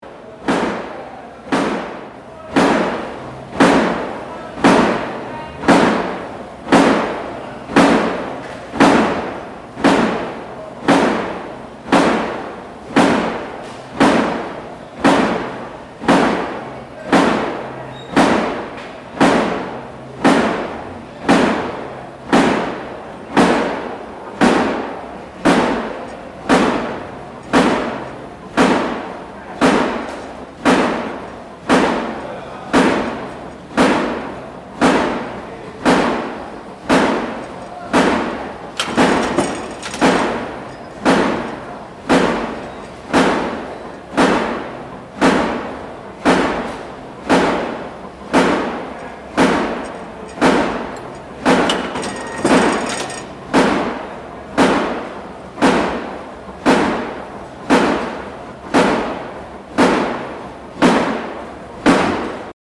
Stamping razor wire for border fences in Marianosztra prison
This recording was made in early 2016, while gathering material for a BBC radio documentary about Hungary's hardline migration policy. Inmates in the prison behind the Marianosztra Monastery were feeding metal tape through stamping machines to make razor wire to fortify Hungary's border fences against migrants and refugees.